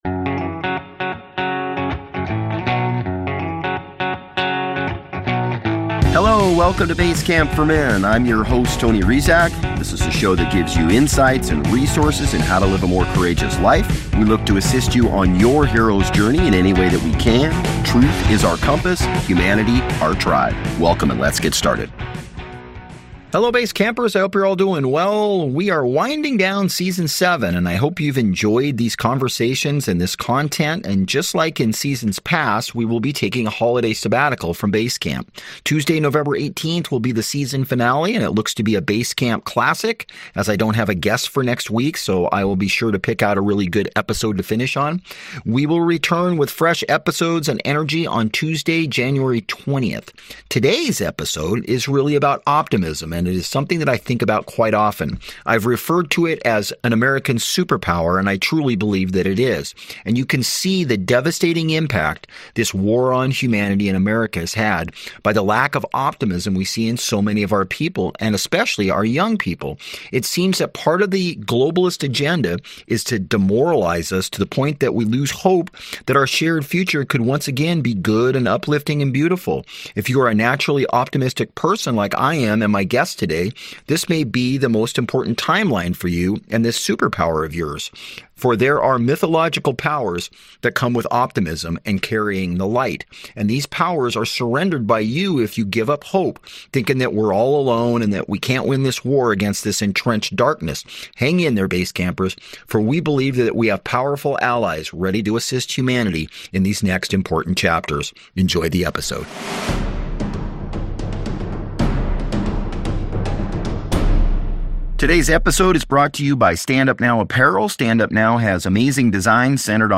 We are winding down Season #7 and I hope you have enjoyed the conversations and content.